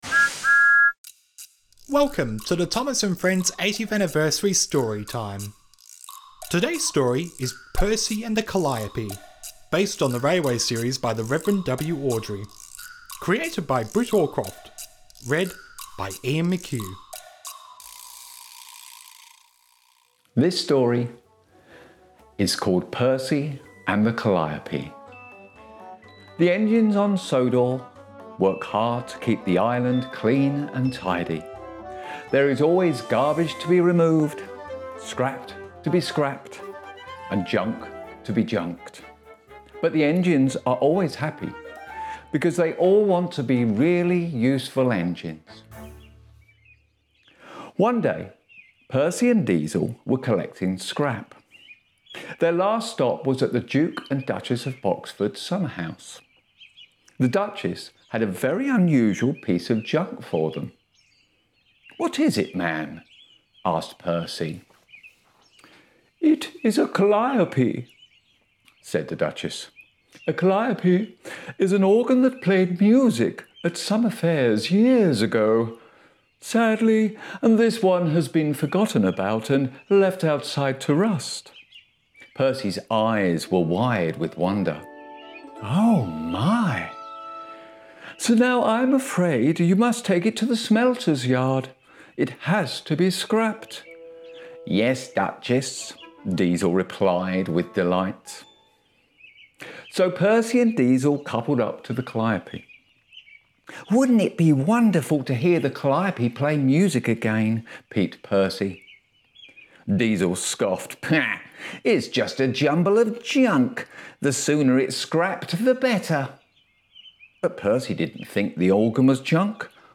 In today’s Thomas & Friends™ 80th Anniversary podcast story for kids, Mark Moraghan reads the Story of when Harold the Helicopter was showing off, so Percy and his driver decided to surprise him.